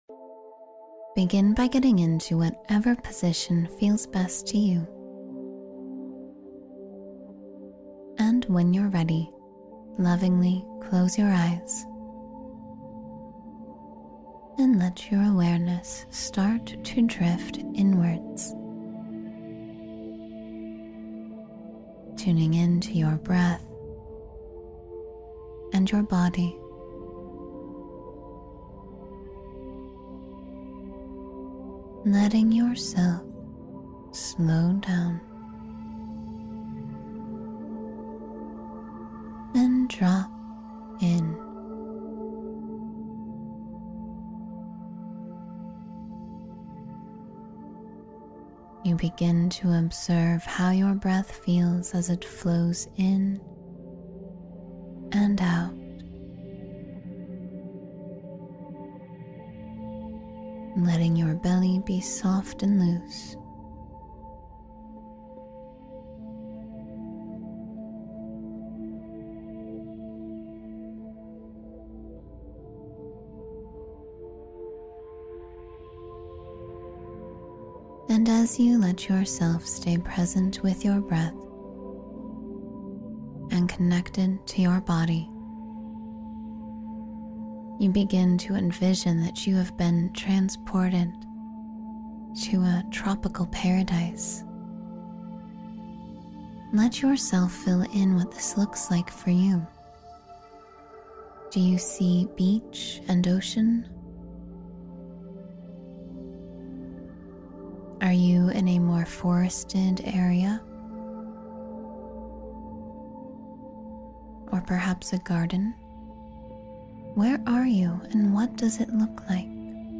Visualization to Calm Your Mind